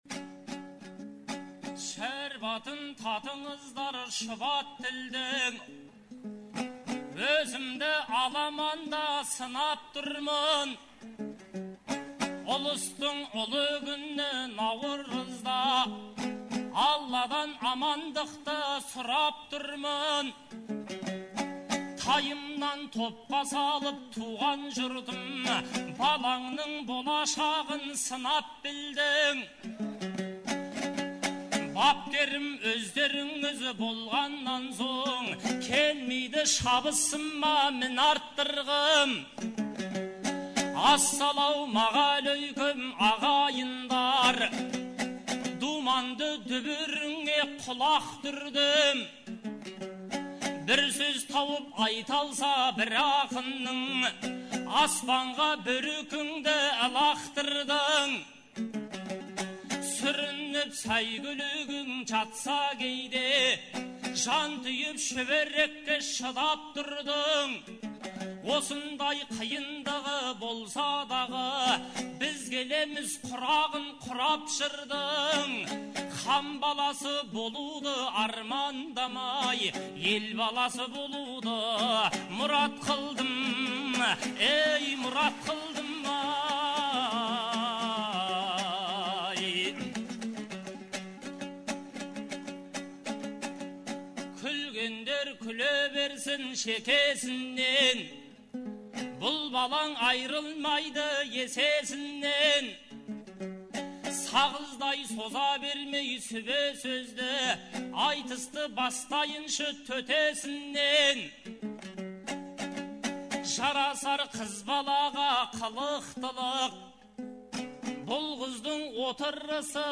Наурыздың 15-16-сы күндері Шымкент қаласында республикалық «Наурыз» айтысы өтті. 2004 жылдан бері өтіп келе жатқан бұл айтыс биыл Төле бидің 350 жылдығына, Абылай ханның 300 жылдығына арналды.